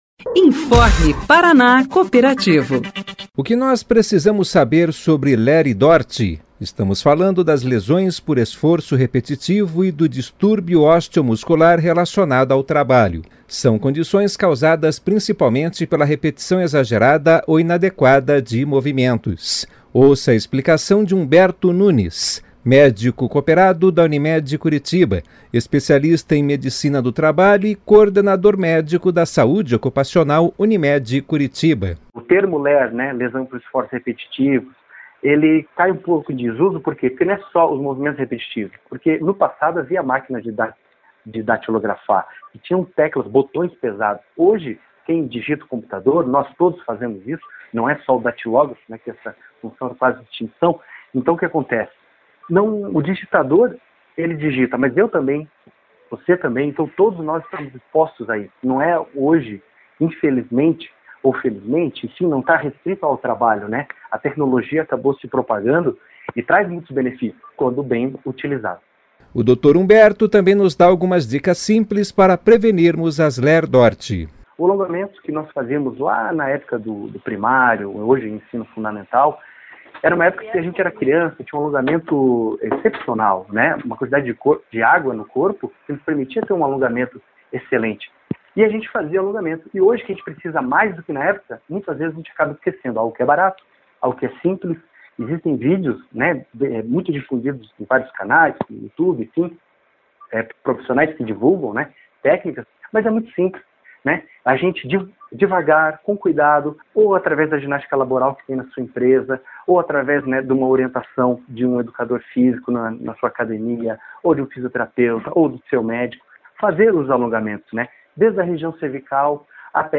São condições causadas principalmente pela repetição exagerada ou inadequada de movimentos. Ouça a explicação